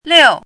chinese-voice - 汉字语音库
liu4.mp3